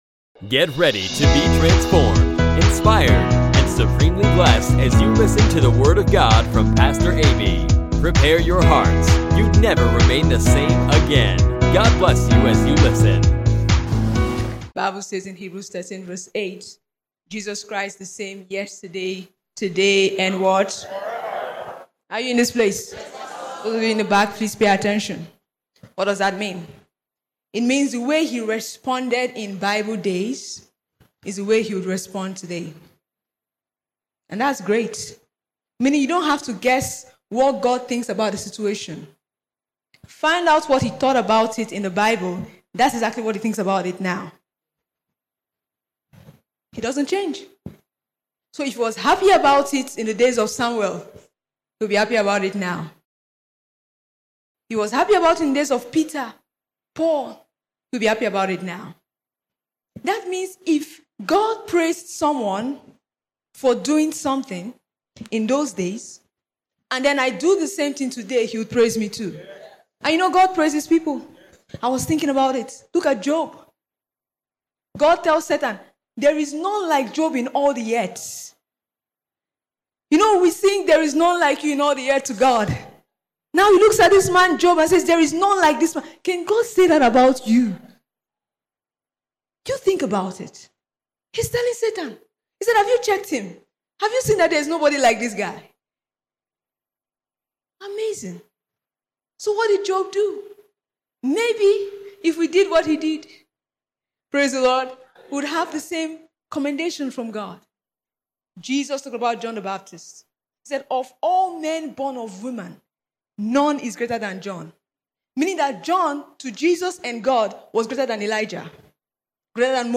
Pastor teaches on God’s pleasure